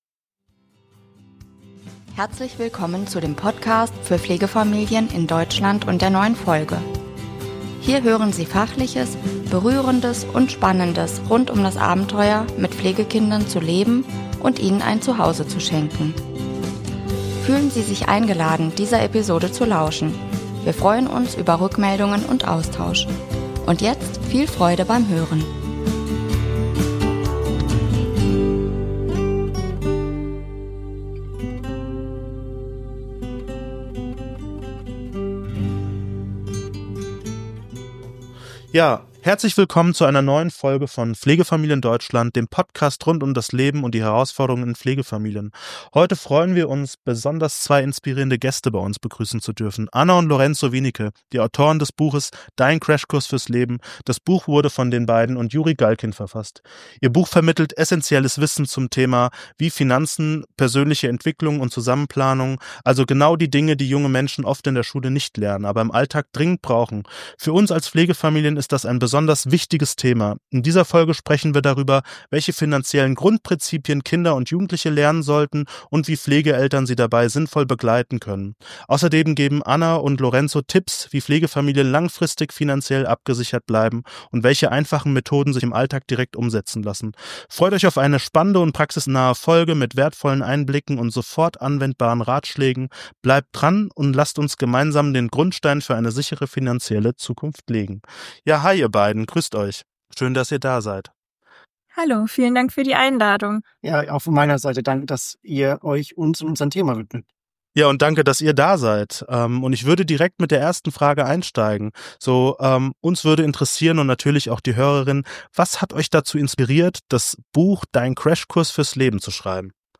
Heute freuen wir uns besonders, zwei inspirierende Gäste bei uns begrüßen zu dürfen